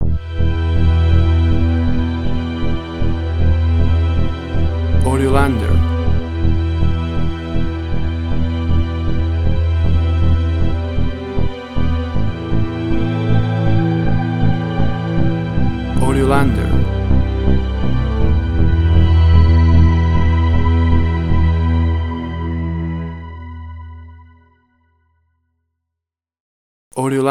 Electronic smooth suspense
Tempo (BPM): 79